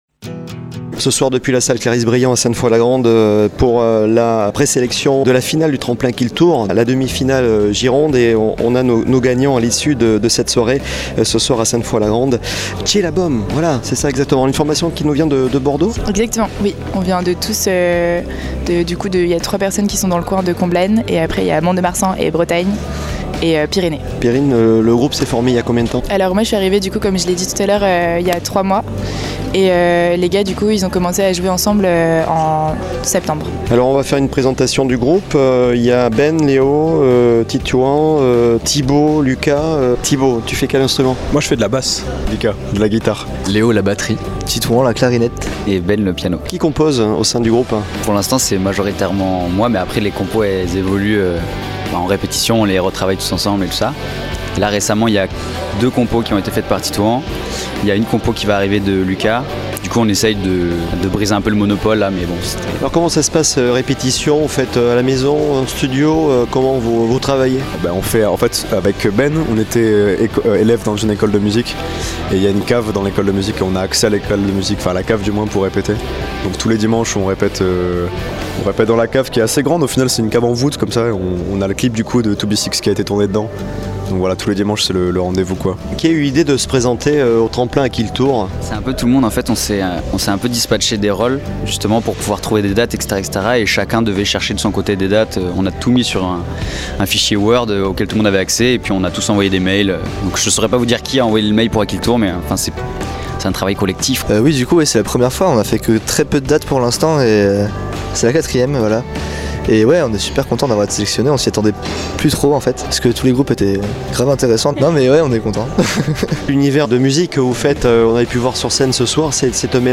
Interview de CHELABÔM sur Radio Grand "R"
Le jury a sélectionné le groupe Bordelais CHELABÔM qui participera à la finale du tremplin le 15 Juin à Bergerac au Rocksane. Retrouvez Radio Grand "R" l'interview du groupe !